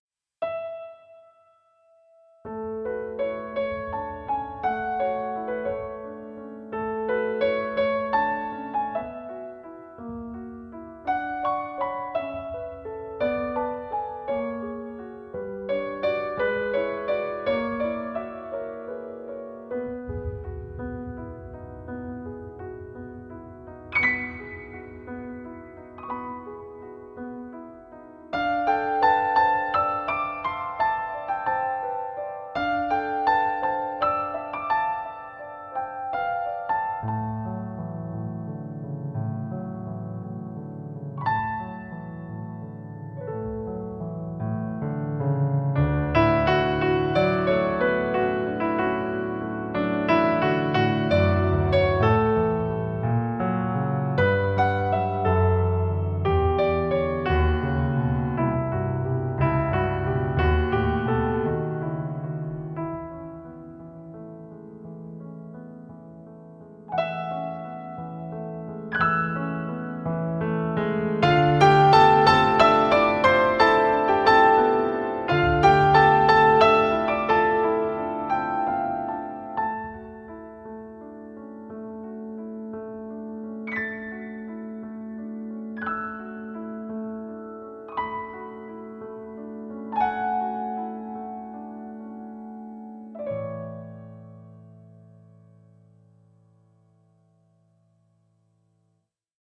ピアノソロ